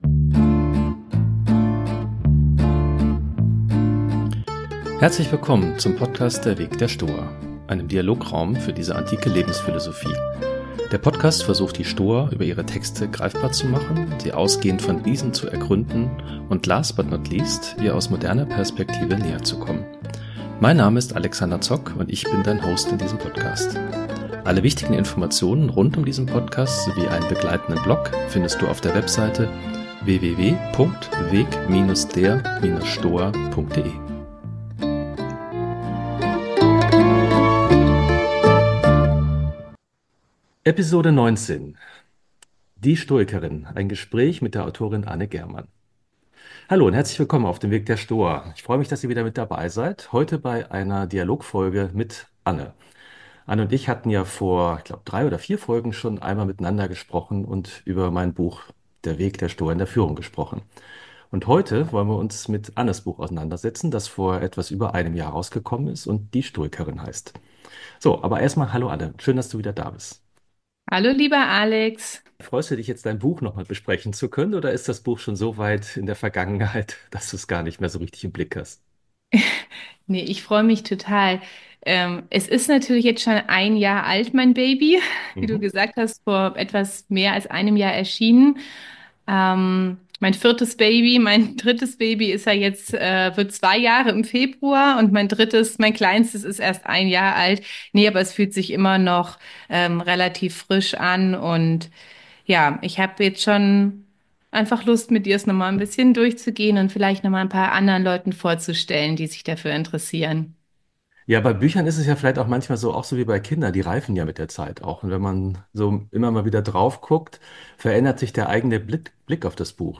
Ein sehr persönliches Gespräch